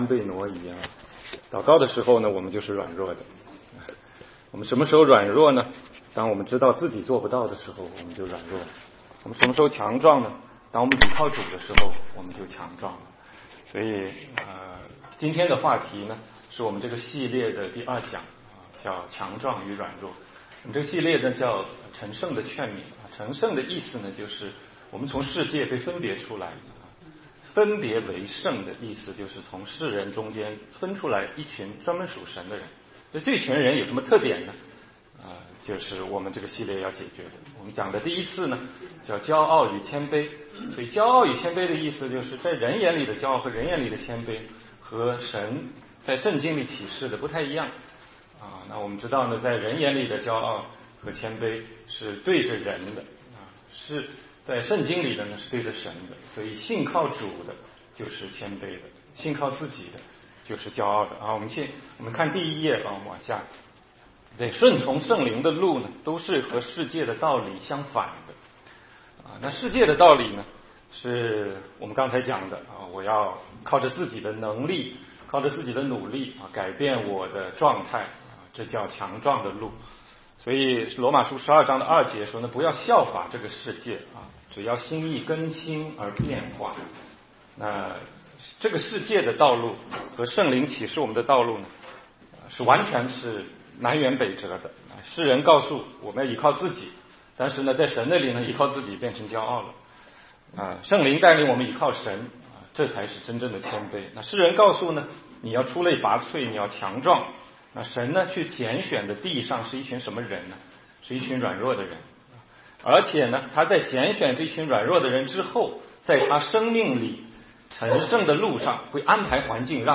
16街讲道录音 - 全中文查经